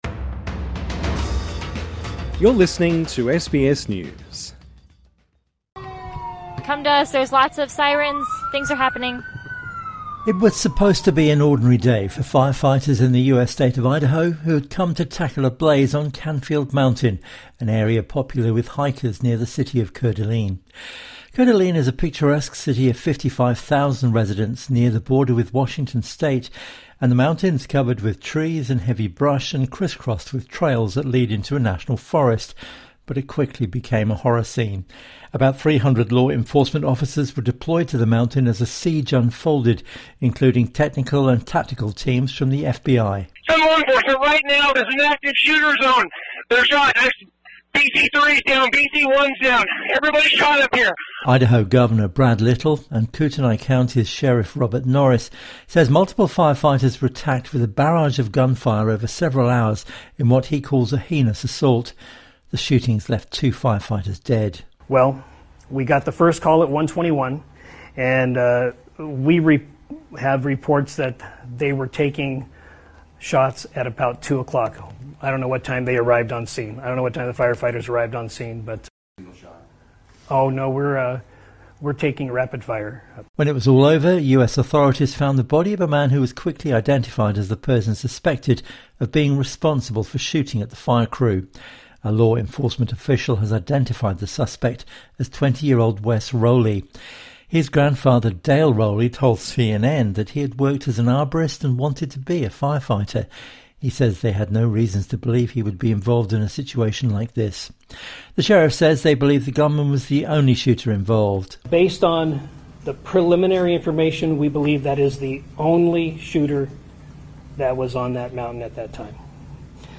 [[SOUND EFFECT: SIRENS]] It was supposed to be an ordinary day for firefighters in the US state of Idaho, who had come to tackle a blaze on Canfield Mountain, an area popular with hikers near the city of Coeur d'Alene.